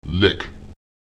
Lautsprecher lek [lEk] um zu